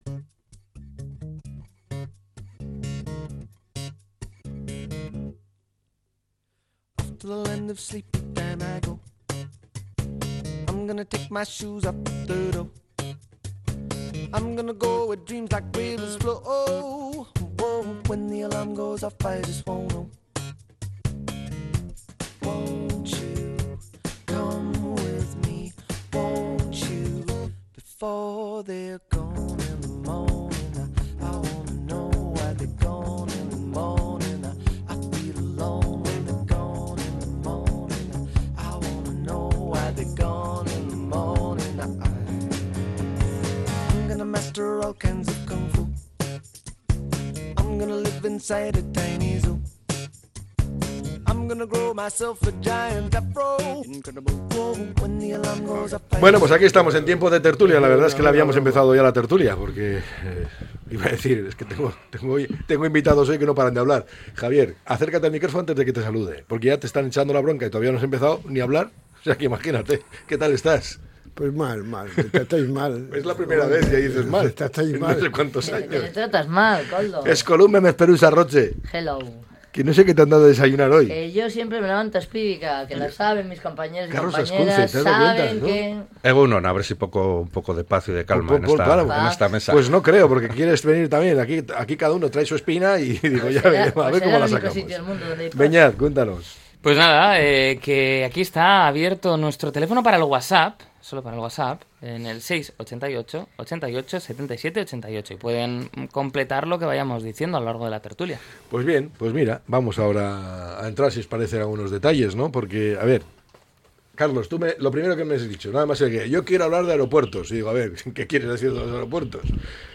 La Tertulia 17-02-26.